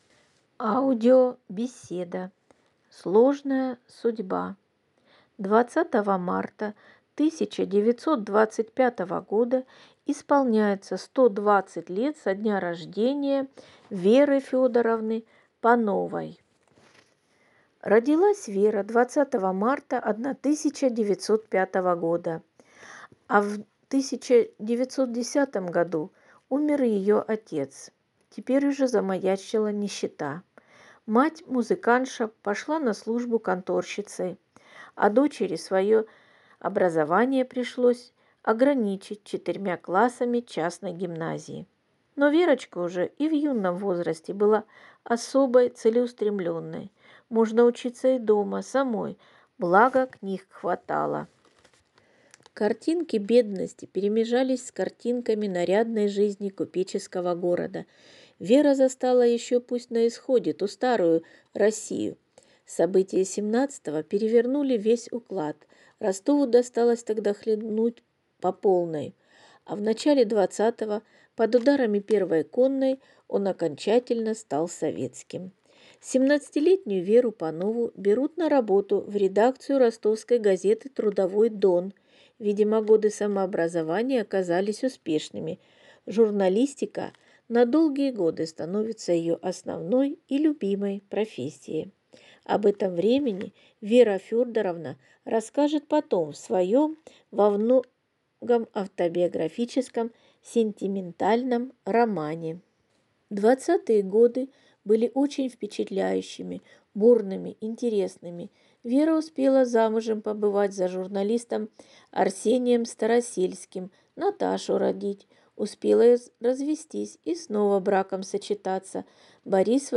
Аудиобеседа «